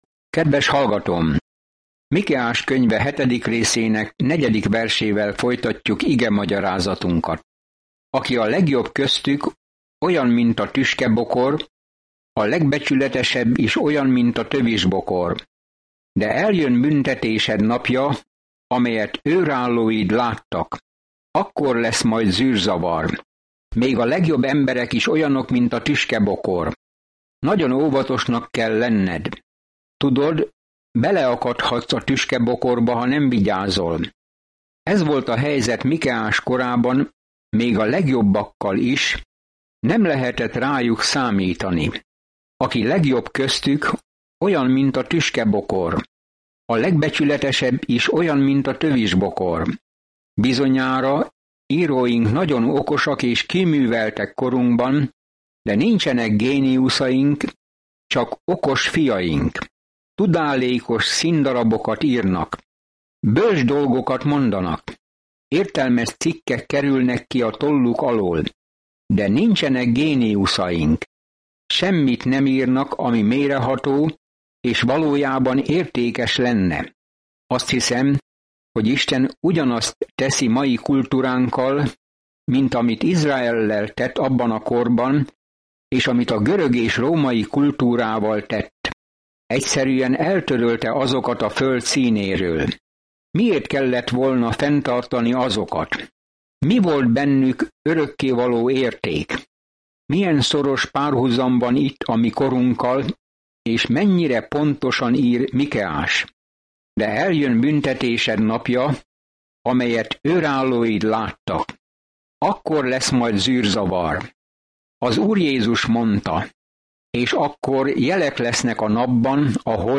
Napi utazás Mikeán, miközben hallgatod a hangos tanulmányt, és olvasol válogatott verseket Isten szavából.